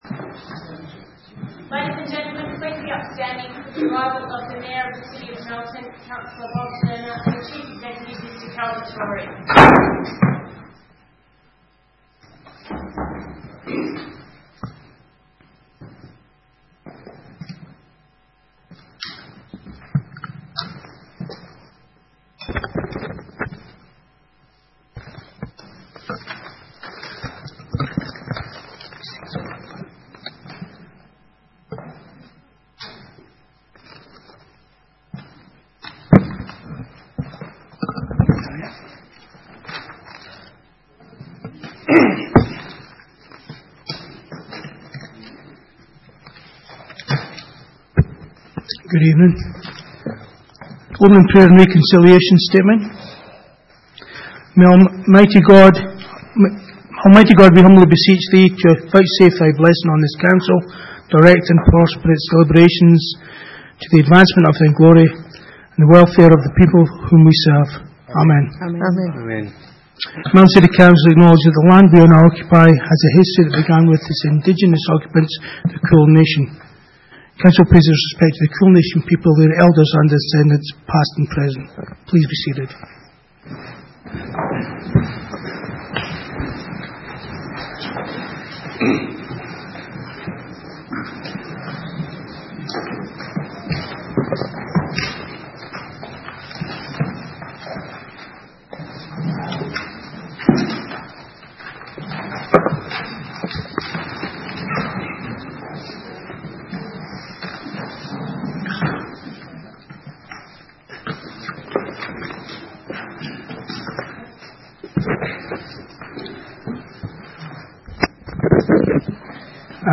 Ordinary Meeting - 26 March 2018